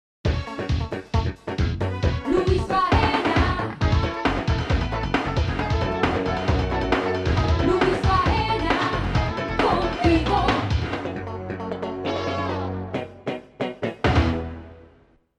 "Jingle"